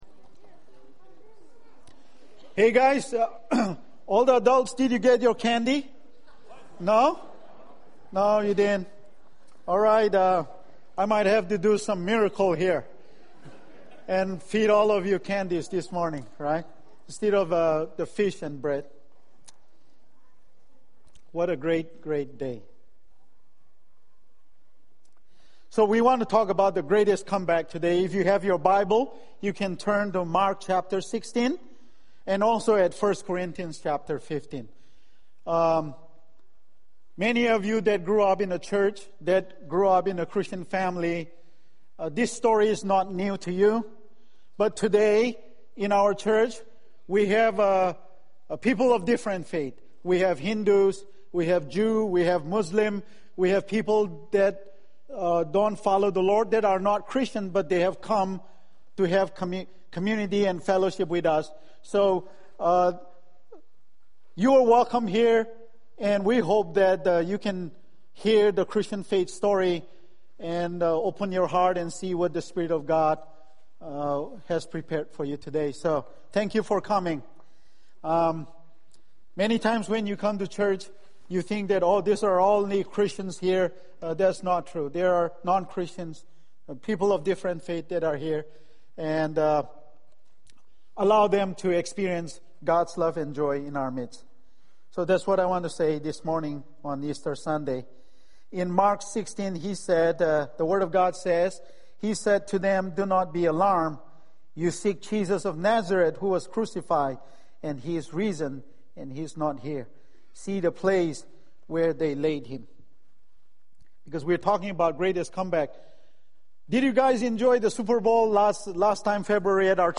Easter message. Three things you need to make a comeback.